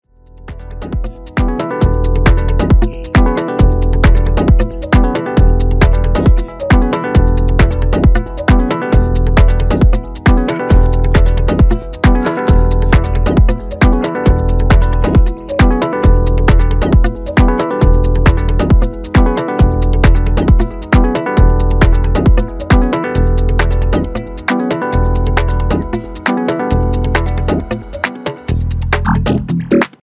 さりげなく複雑で繊細なエフェクトの妙味が完全に新世代の感覚